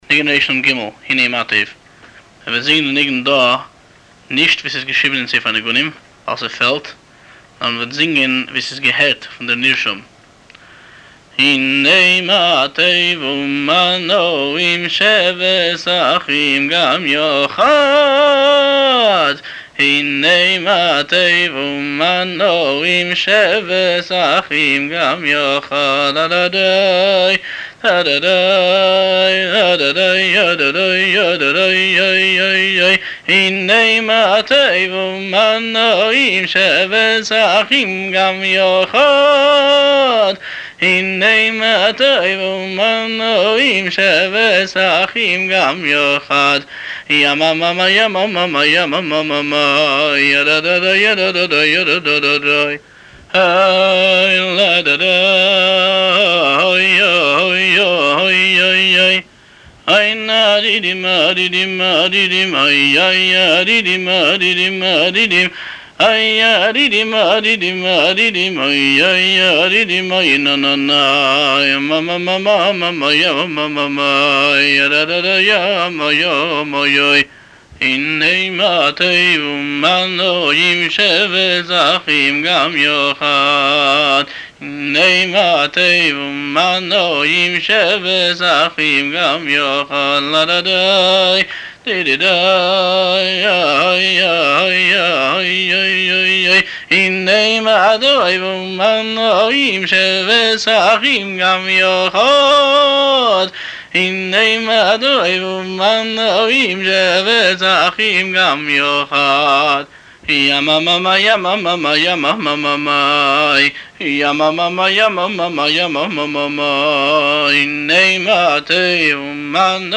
הניגון
הבעל-מנגן